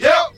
Tm8_Chant73.wav